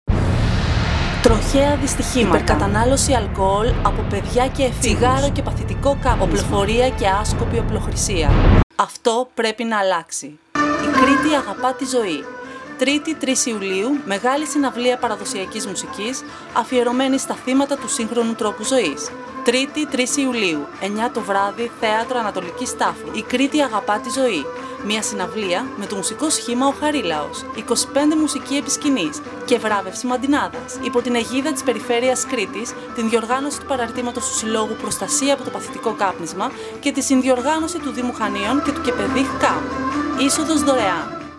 Χανιά: Συναυλία Παραδοσιακής Κρητικής Μουσικής (audio)